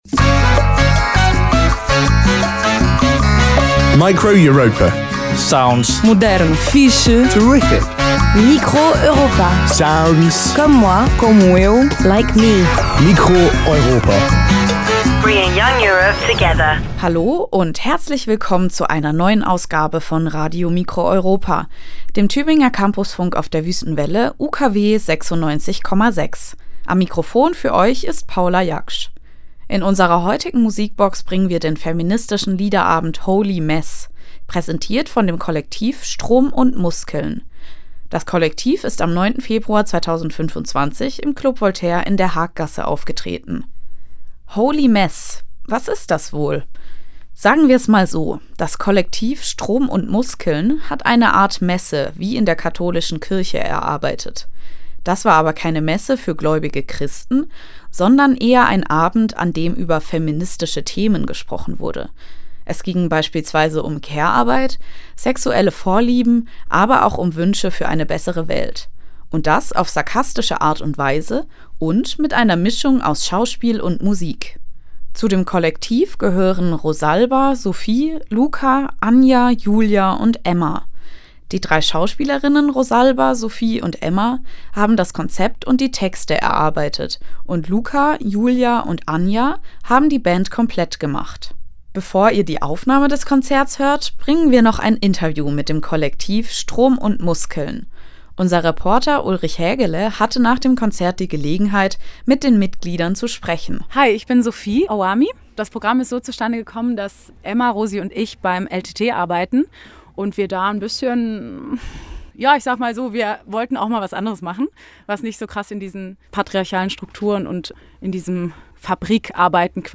Musikbox: Feministischer Liederabend Holly Mess, live im Club Voltaire
Es ging um Care-Arbeit, sexuelle Vorlieben, aber auch um Wünsche für eine bessere Welt. Und das auf sarkastische Art und Weise und mit einer Mischung aus Schauspiel und Musik!
Form: Live-Aufzeichnung, geschnitten